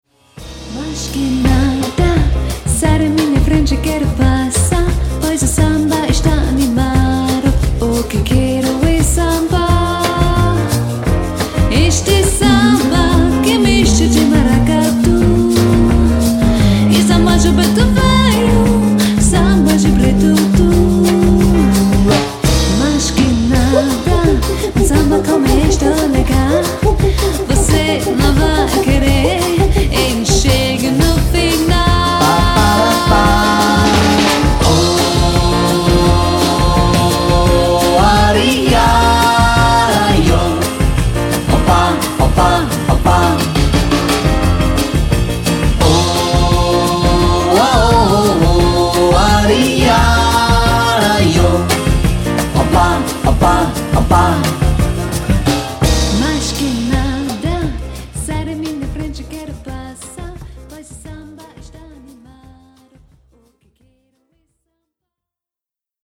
jazz can dance